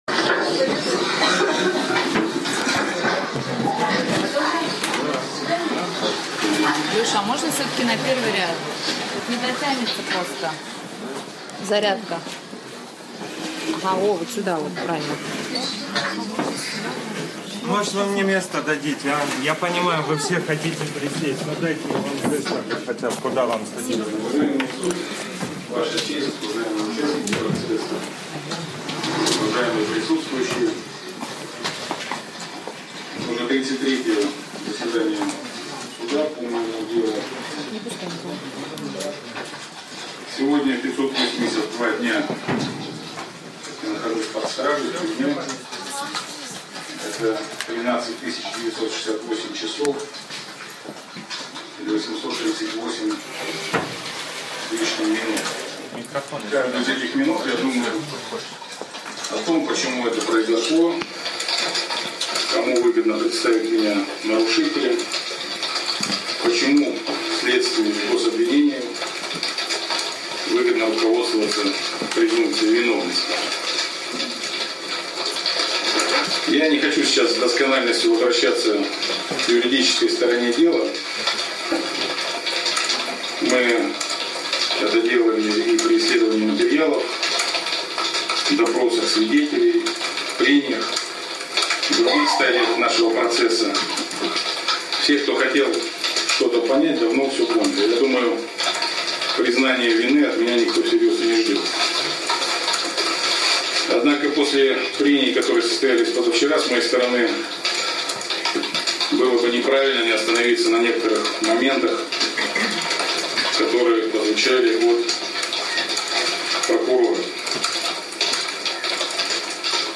Никита Белых выступил в суде с последним словом